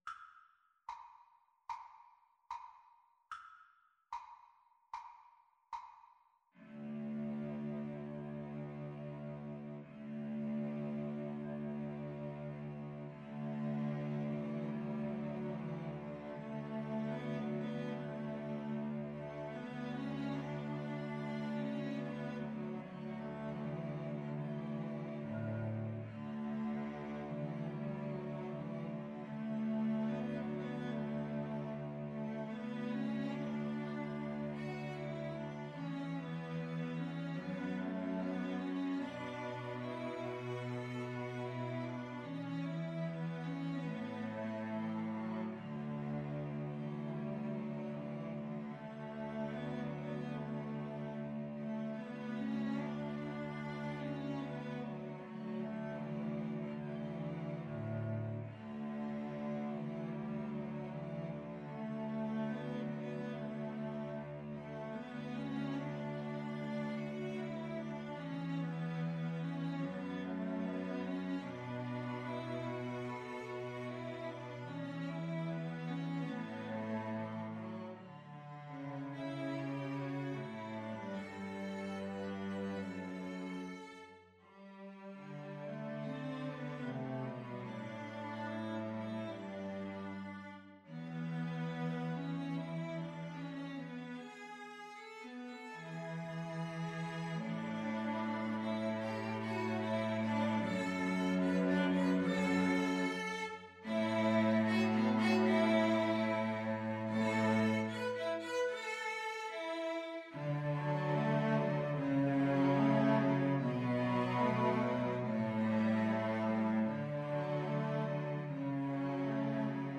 Free Sheet music for Cello Trio
Andantino quasi allegretto ( = 74) (View more music marked Andantino)
D major (Sounding Pitch) (View more D major Music for Cello Trio )
Classical (View more Classical Cello Trio Music)